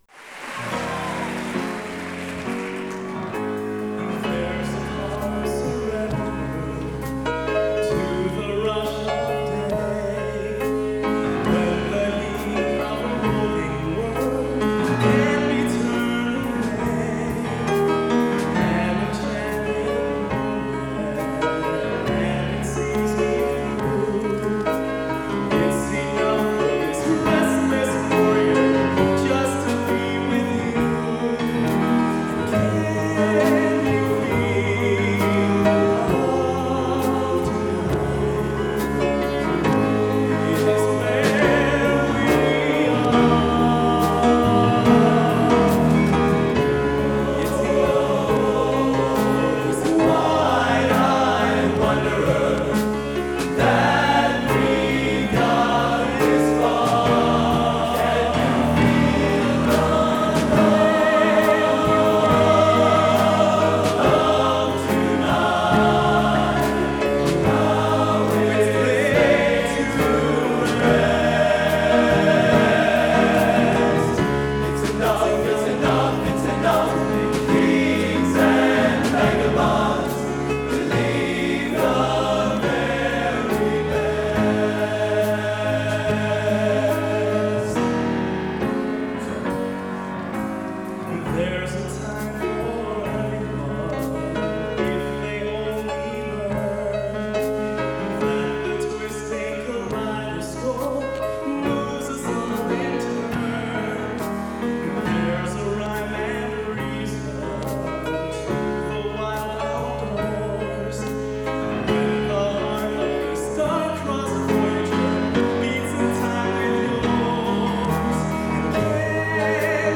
Genre: Cinema | Type: Solo